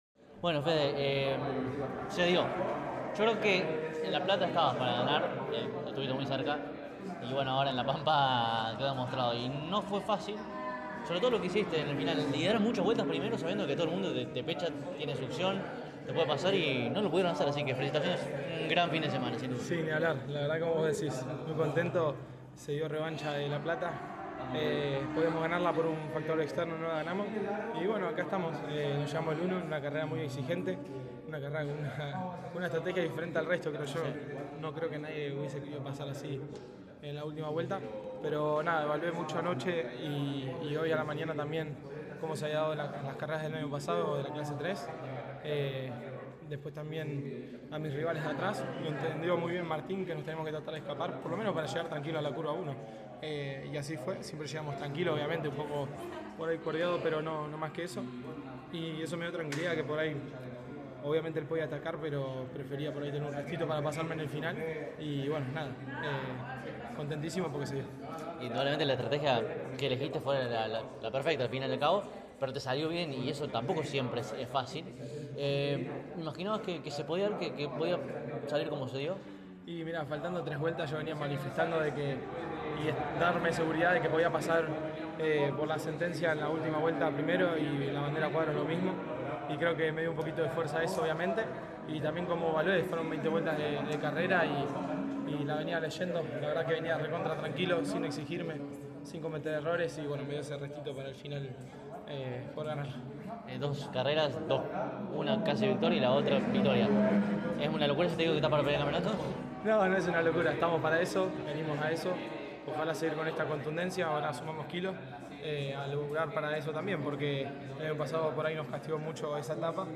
Por lo tanto, a continuación solo escucharás los testimonios de los dos primeros.